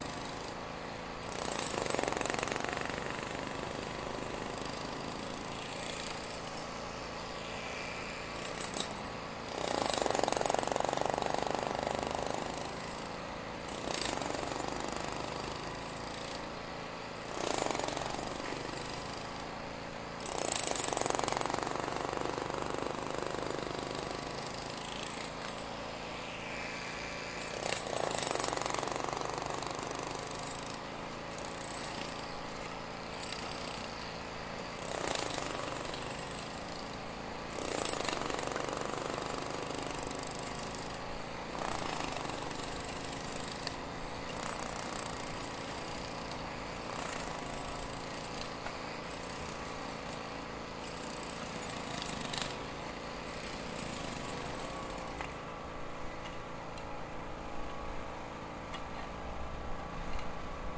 描述：从bahcesehir大学对面的窗户，工人做一些建设
Tag: 制造商 嗡嗡声 钻孔 敲打 金属 工作 建筑 电力工具 起重机 钻孔 施工 工人 机械 嗡嗡声 噪音 锤击 弹跳 城市 高层 建设 工业